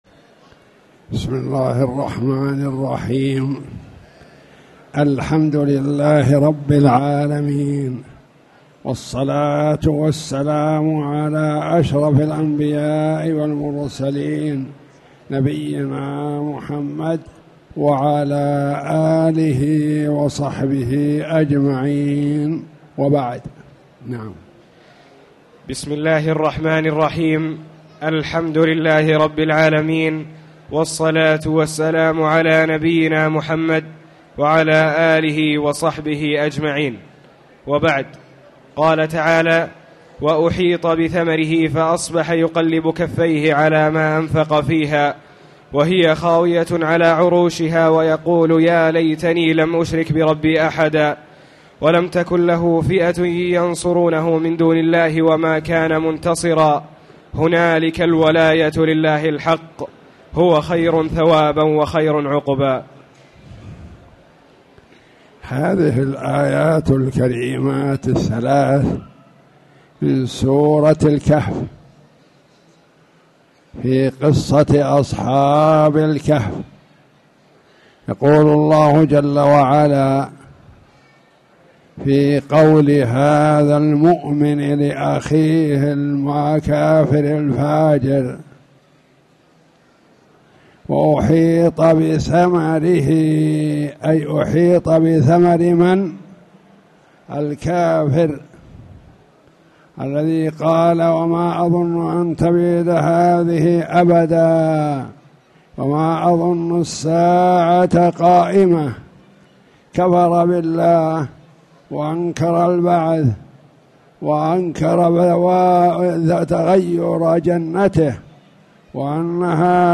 تاريخ النشر ١٨ شوال ١٤٣٨ هـ المكان: المسجد الحرام الشيخ